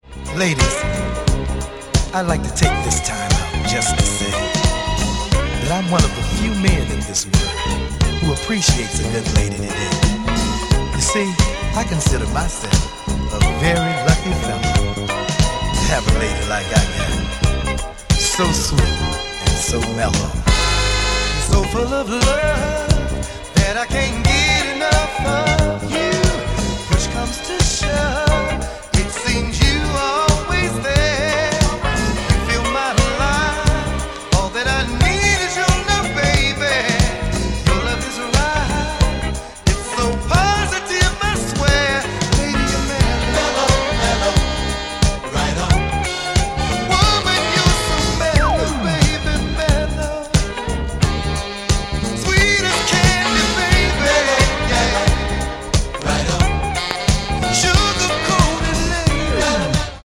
Classic down beat sultry soul